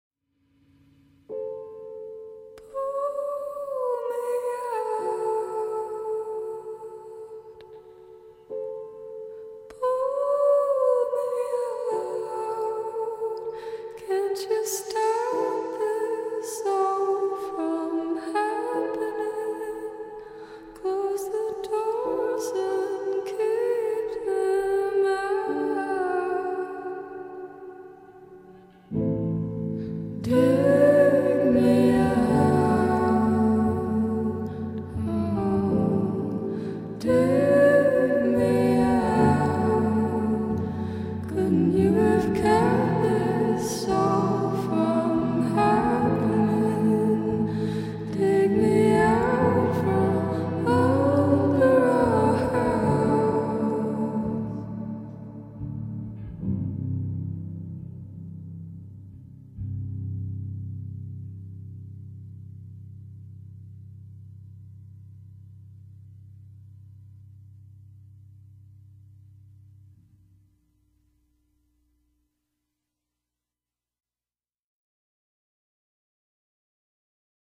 Two speakers look at each other.
With a sharp, perfect vocality.